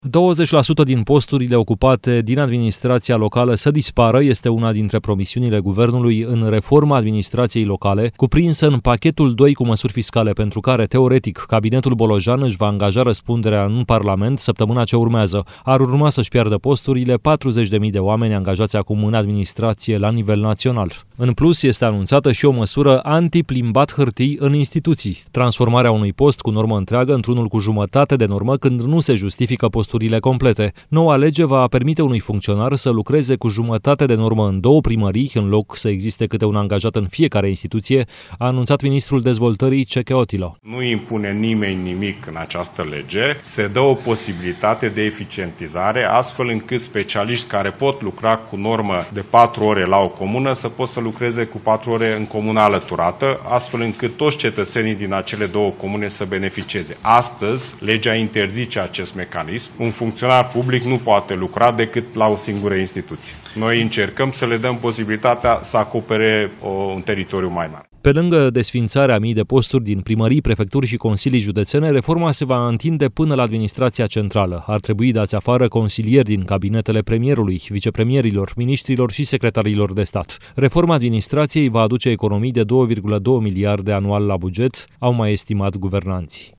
Ministrul Dezvoltării, Cseke Attila: „Se dă o posibilitate de eficientizare”